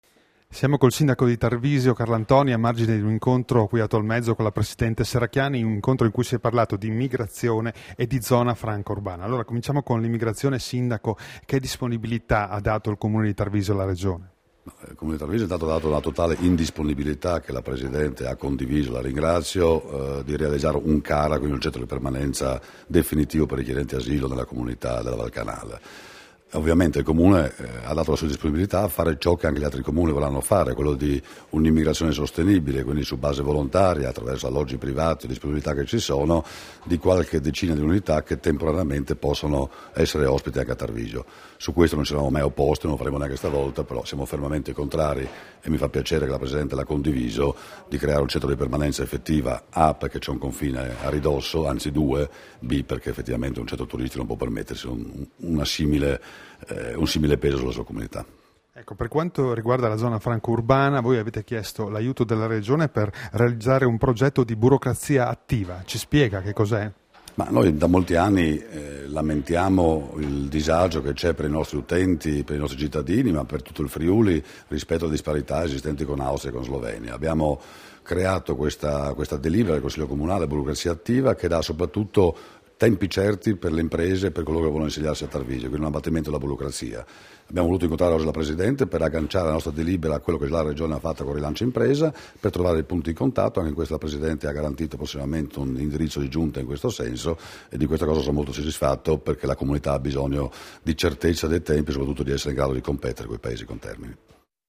Dichiarazioni di Debora Serracchiani (MP3) [1356KB]
a margine dell'incontro con il sindaco di Tarvisio sul progetto dell'accoglienza diffusa in materia di immigrazione, rilasciate a Tolmezzo il 9 febbraio 2015